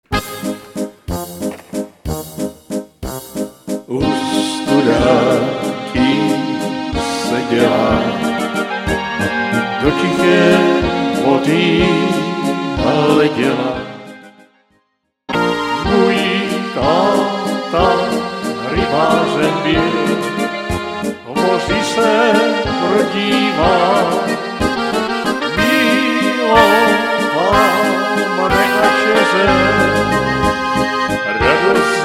Rubrika: Národní, lidové, dechovka
- směs - valčík